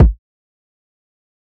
TC Kick 09.wav